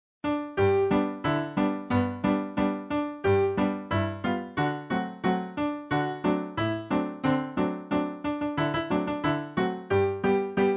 Traditional Folk Song